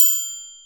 triangle overused.wav